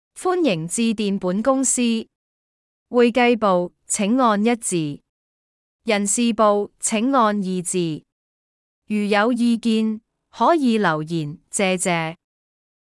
真人配音及Voice-over 本公司提供專業配音、旁白、Voice-over 服務，包括真人錄音及AI語音合